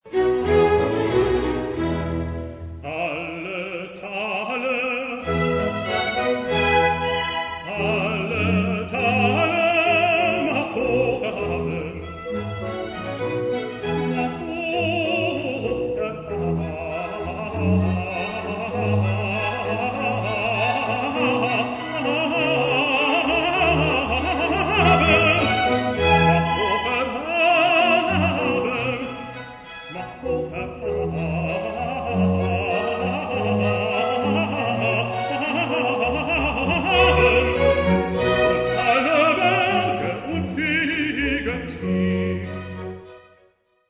Hörbeispiel: Messias Arie Alle Tale